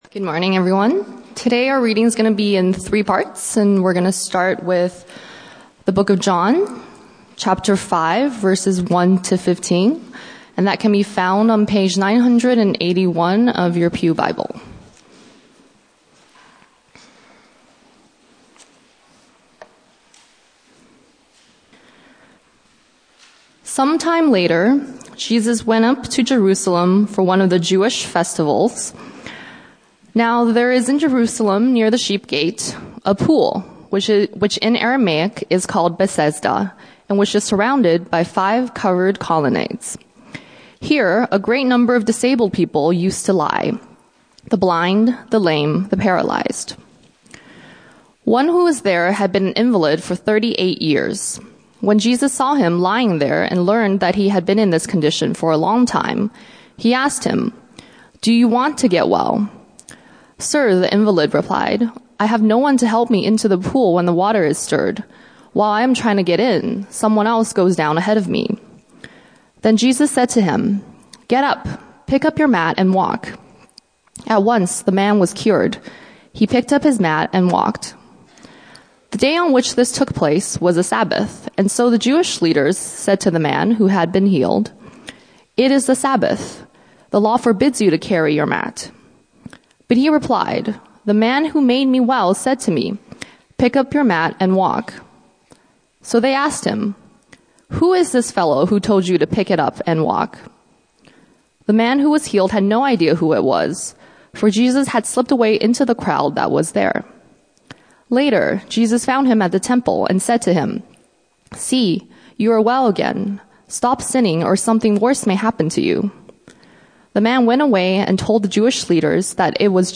Sermons | St. Thomas Mission
Guest Speaker